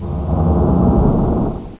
sinking noises.
9sink.wav